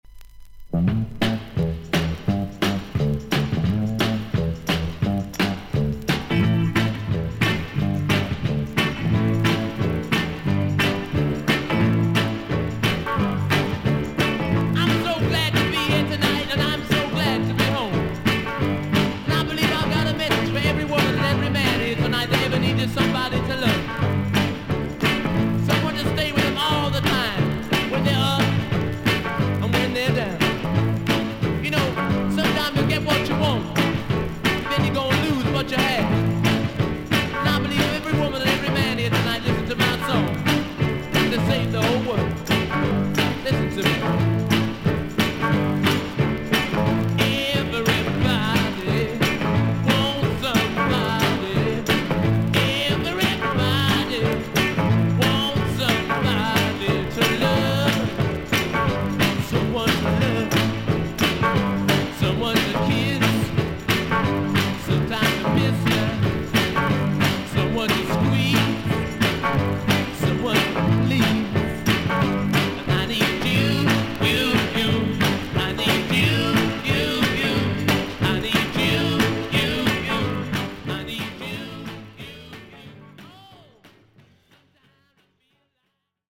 A1に太めのスジが見えますが浅いので音に出ません。
音のグレードはVG+〜VG++:少々軽いパチノイズの箇所あり。少々サーフィス・ノイズあり。クリアな音です。